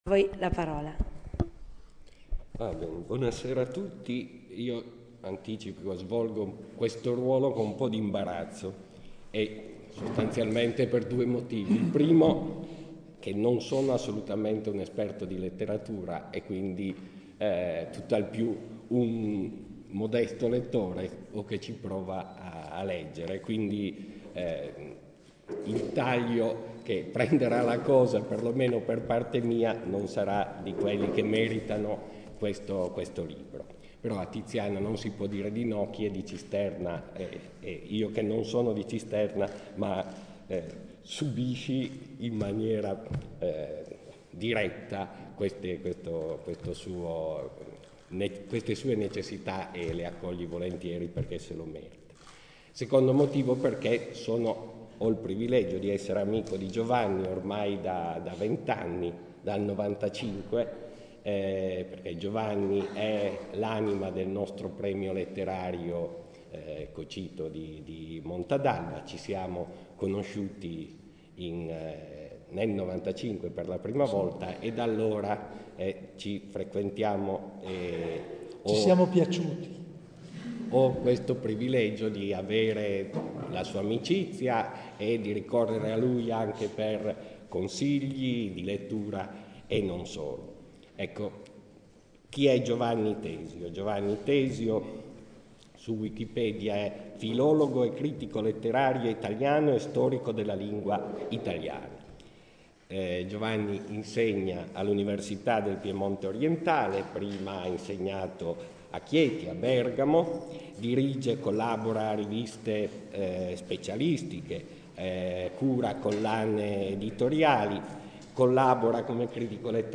26^ INCONTRO DEL POLO CITTATTIVA PER L’ASTIGIANO E L’ALBESE PER L’A.S. ‘14/’15